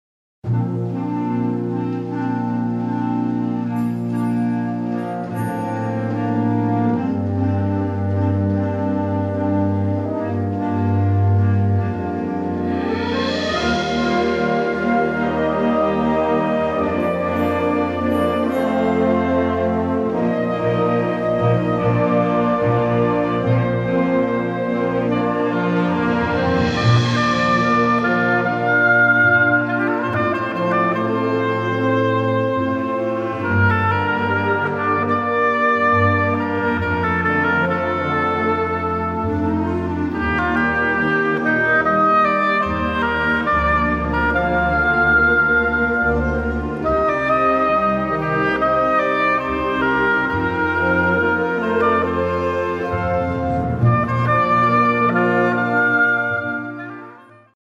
Besetzung: Blasorchester
Solo für Oboe und Fagott.